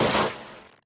car_death.ogg